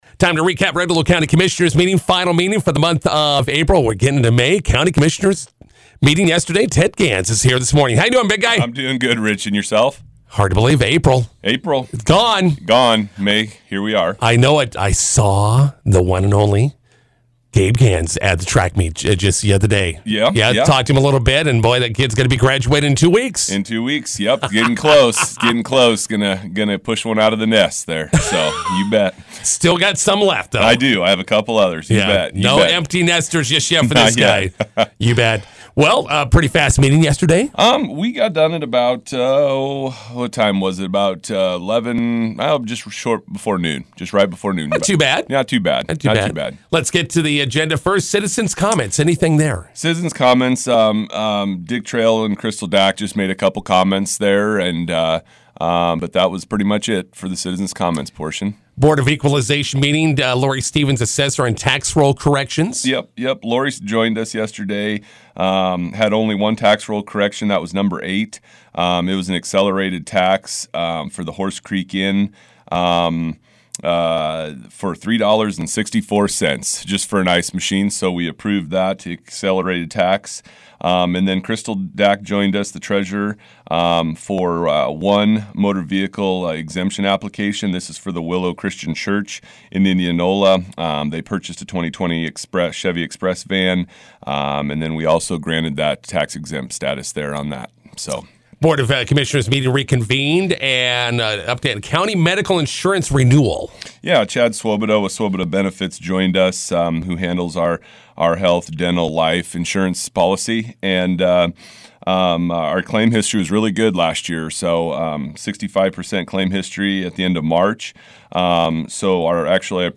INTERVIEW: Red Willow County Commissioners meeting recap with County Commissioner Ted Gans.